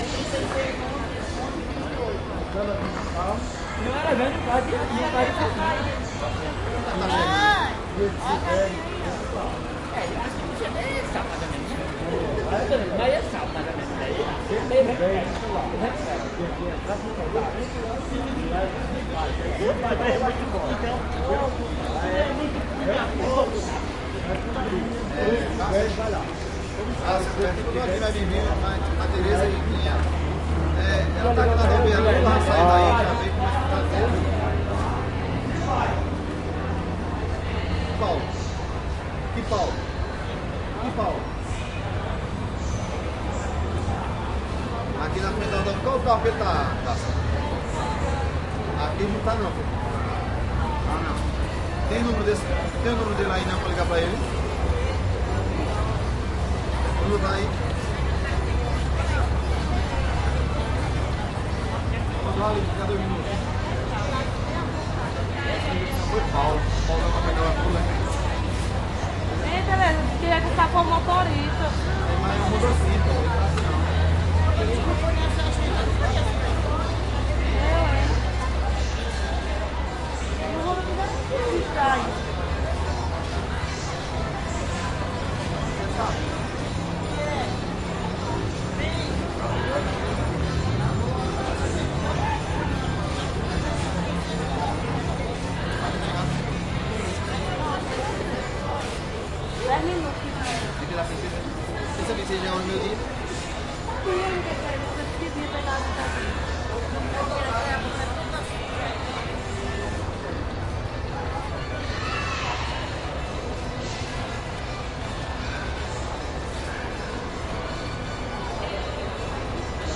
人们在等待公交车时交谈
描述：人们在卡鲁阿鲁汽车站等待公交车时交谈。
Tag: 环境 公交车 说话